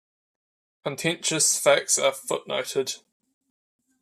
Pronounced as (IPA) /fækts/